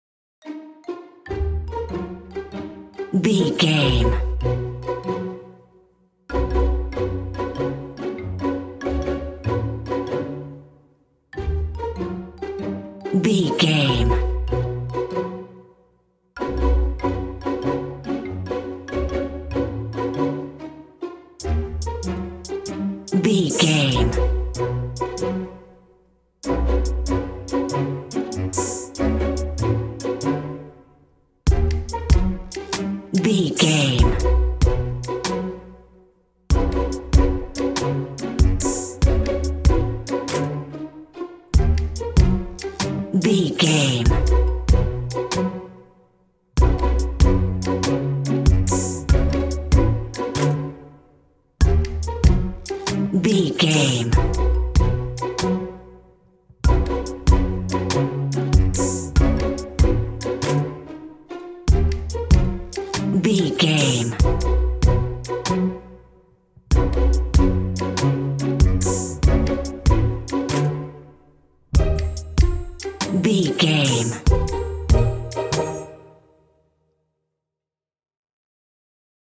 Ionian/Major
orchestra
strings
percussion
flute
silly
circus
goofy
comical
cheerful
perky
Light hearted
quirky